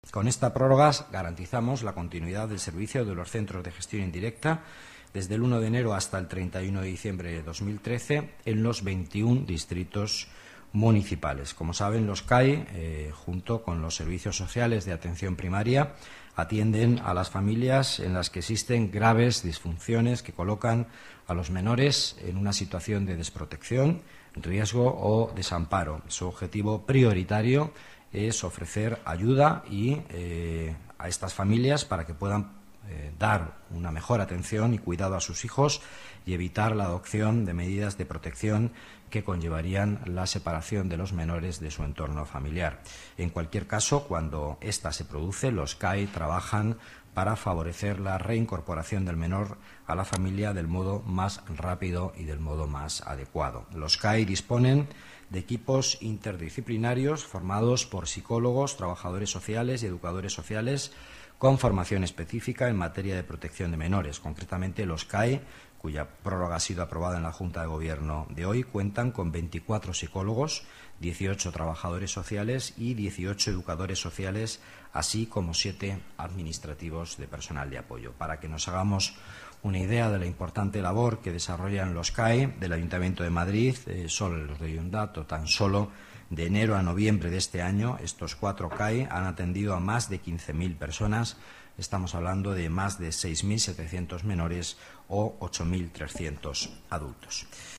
Nueva ventana:Declaraciones del vicelacalde, Miguel Ángel Villanueva: Atención a menores